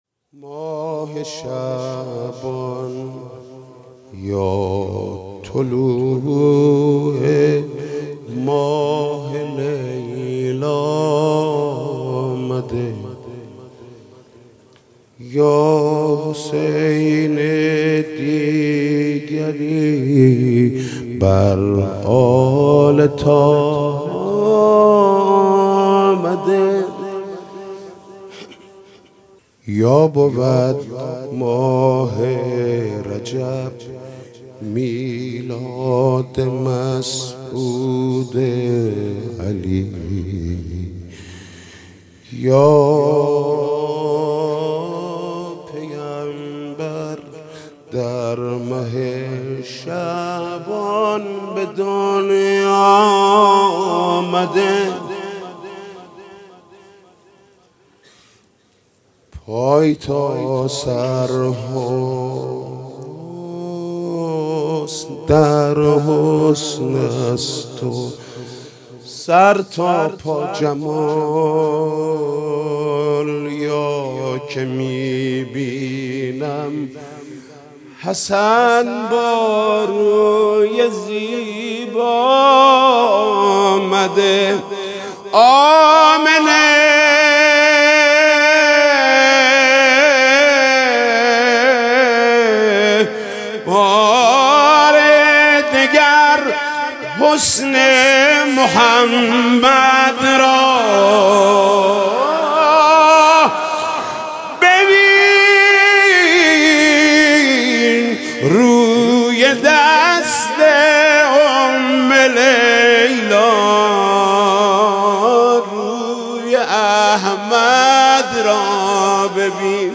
مدح: ماه شعبان یا طلوع ماه لیلا آمده